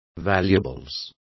Complete with pronunciation of the translation of valuables.